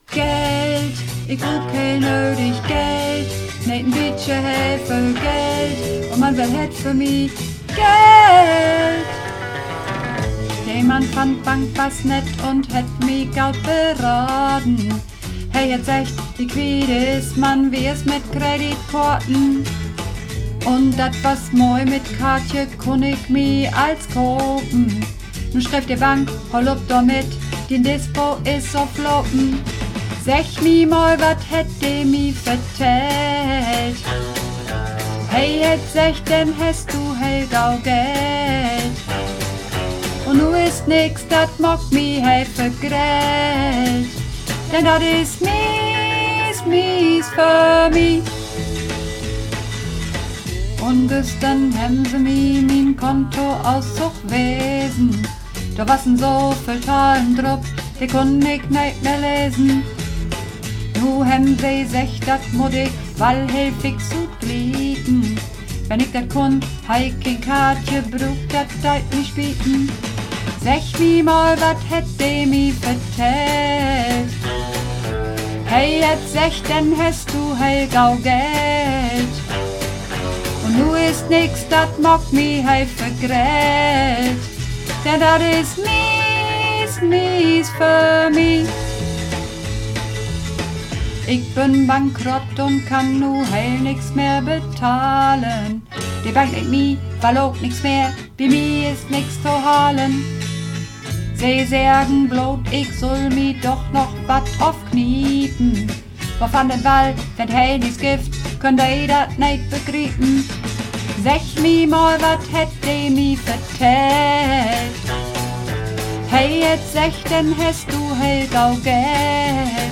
Übungsaufnahmen - Geld
Geld (Alt)
Geld__1_Alt.mp3